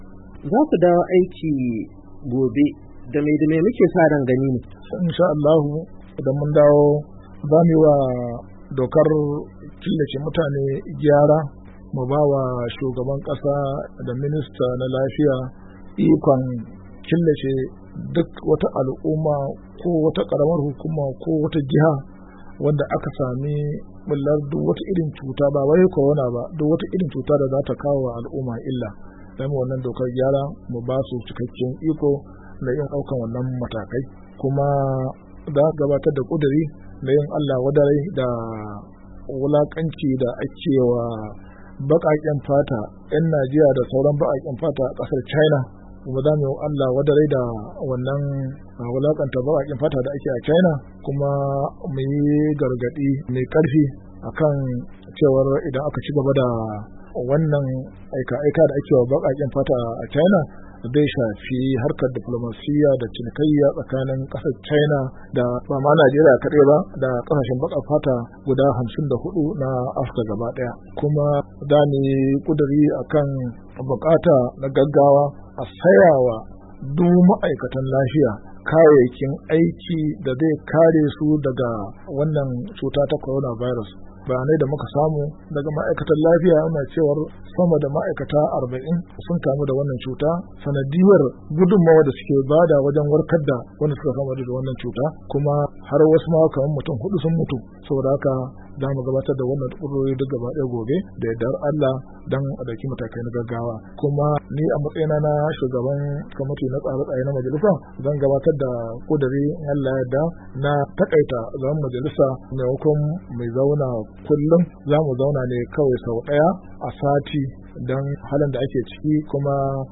Majalisar Dokokin Najeriya za ta dawo aiki gobe Talata 28 ga wata Afrilu, bayan hutun mako hudu wanda ya biyo bayan bullar cutar coronavirus. Shugaban Kwamitin tsare-tsare da walwala na majalisar Abubakar Hassan Fulata ya yi hira ta musamman da wakiliyar muryar Amurka, inda ya ce, idan sun...